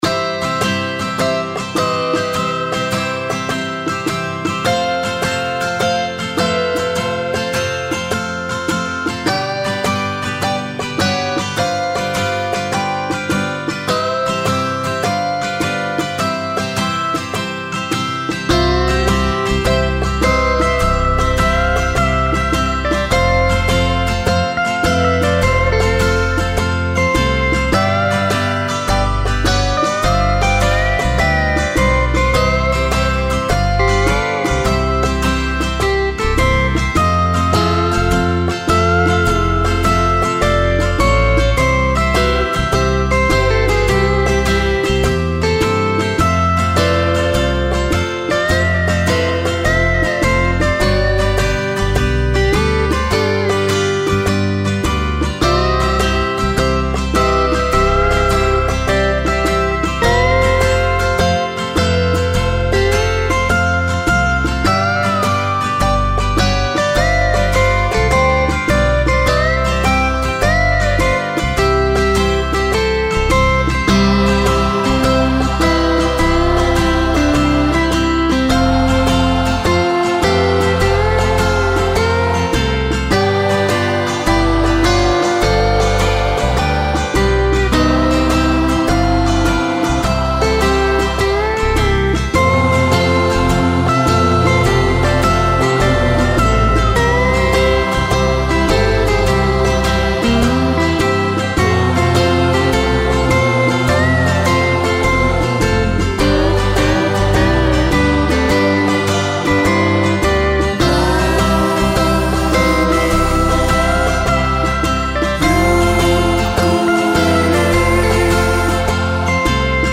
Moderato [100-110] plaisir - banjo - plage - mer - vahine